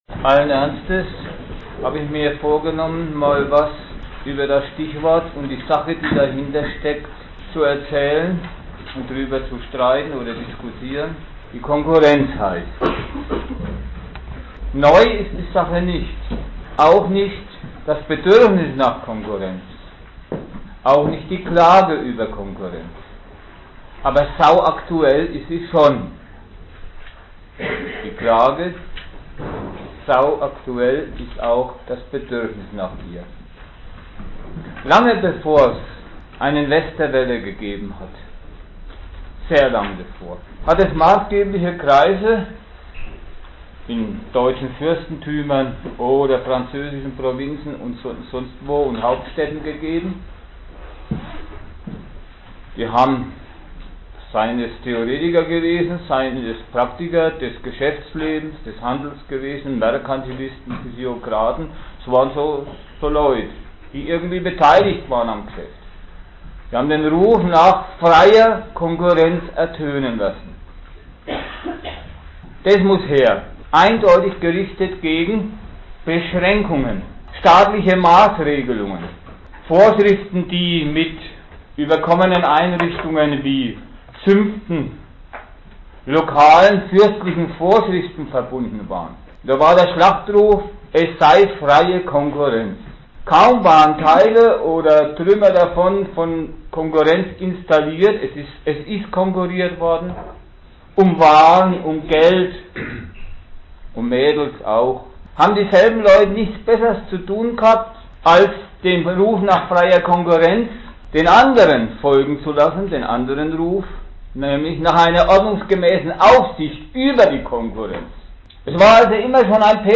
Ort München
Dozent Gastreferenten der Zeitschrift GegenStandpunkt